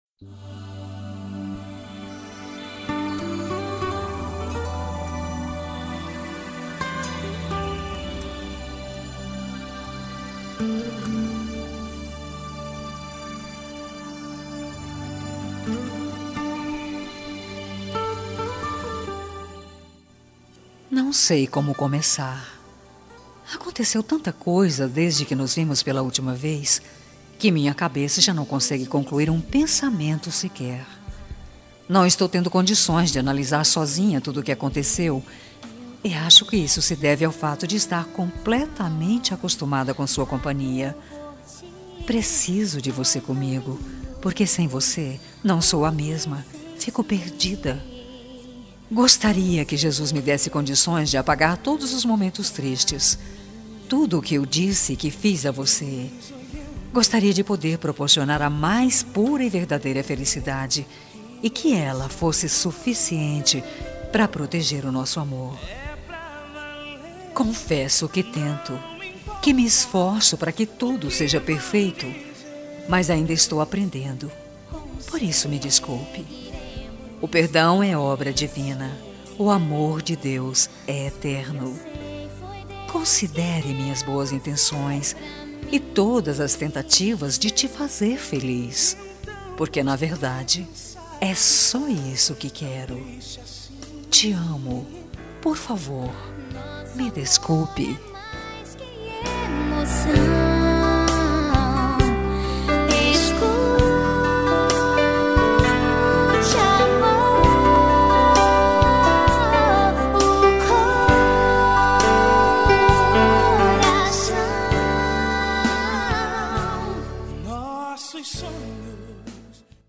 Telemensagem de Desculpas Evangélicas – Voz Feminina – Cód: 09829